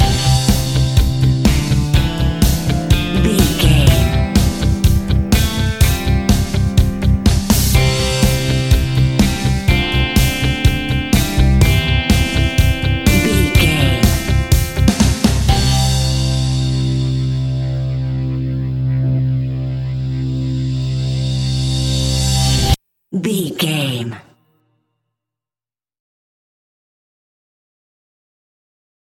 Fast paced
Ionian/Major
indie pop
fun
energetic
uplifting
instrumentals
guitars
bass
drums
piano
organ